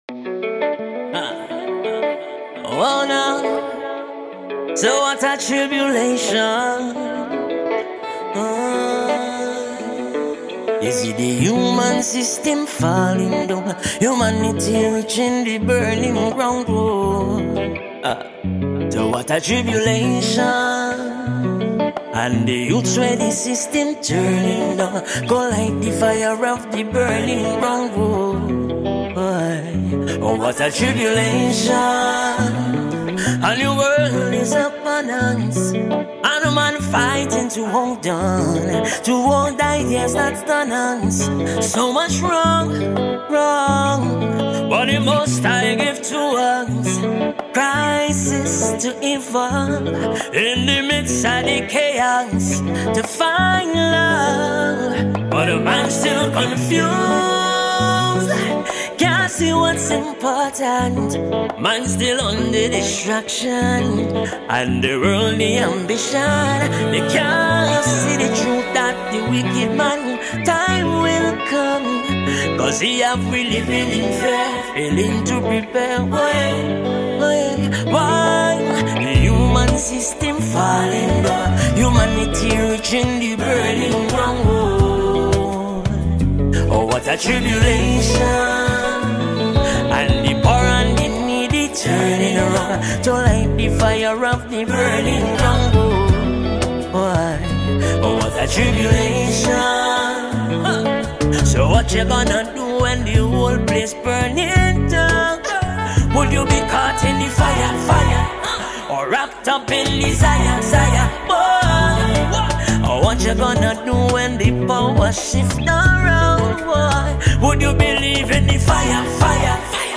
Background Vocals
Live Saxophone